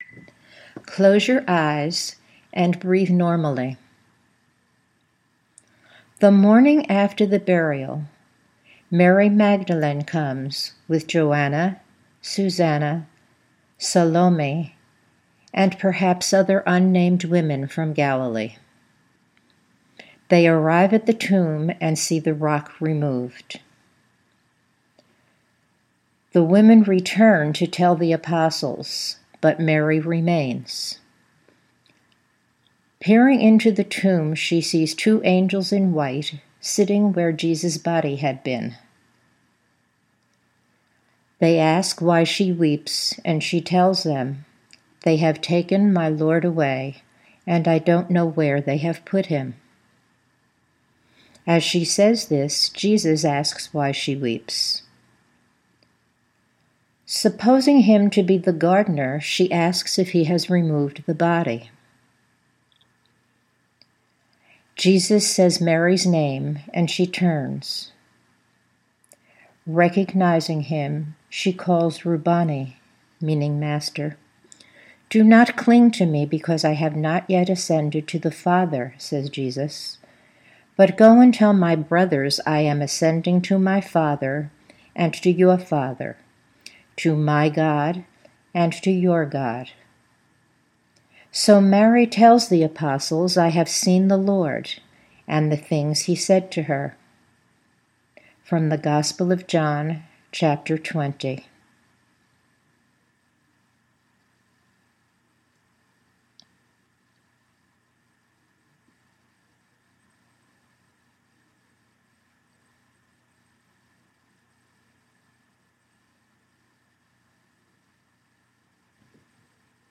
Narrative of the recorded contemplation called Mary Magdala Sees The Resurrected Jesus.
There are moments of silence after the reading.